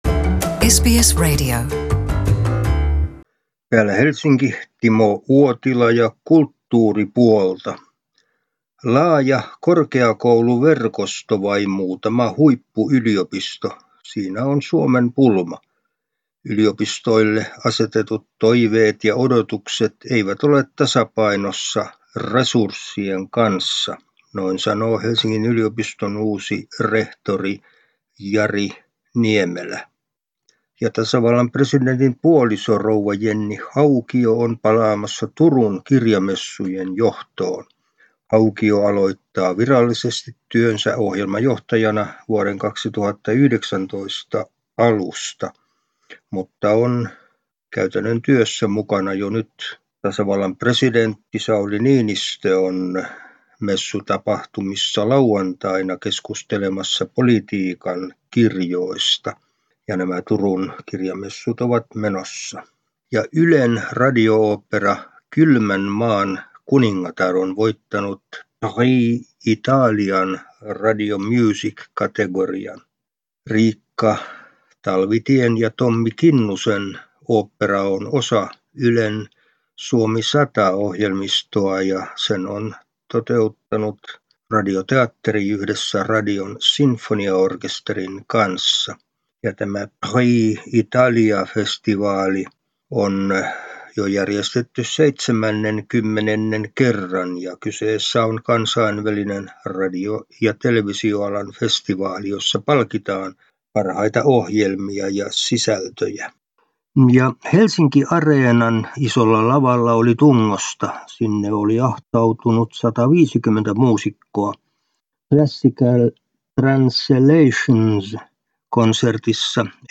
kulttuuriraportti Suomesta